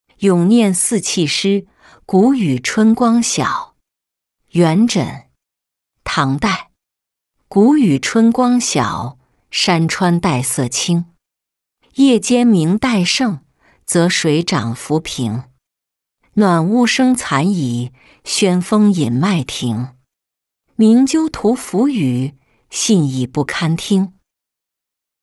咏廿四气诗·谷雨春光晓-音频朗读